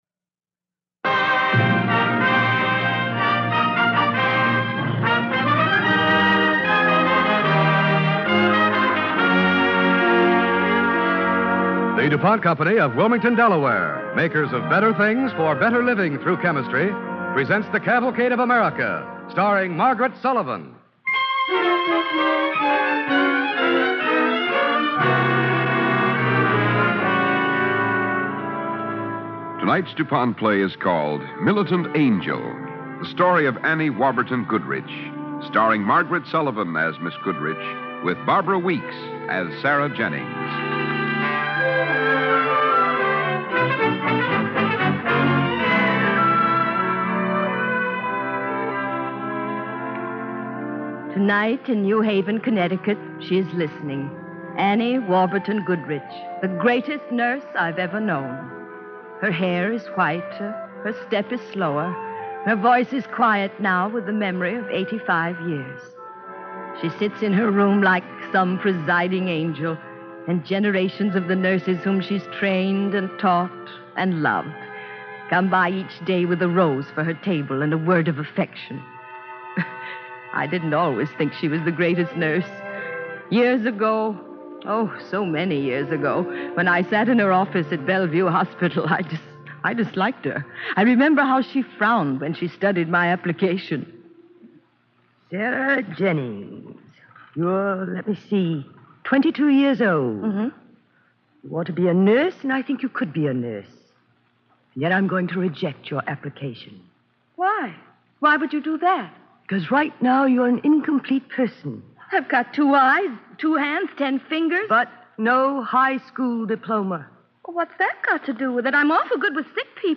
starring Margaret Sullivan and Barbara Weeks
Cavalcade of America Radio Program